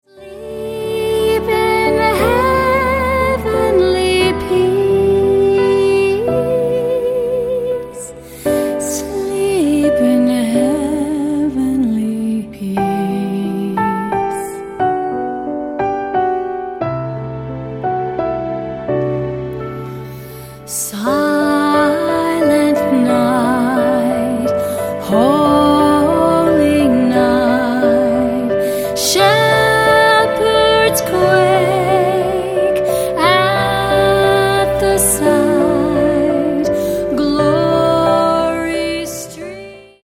Piano - Strings - Low - Vocal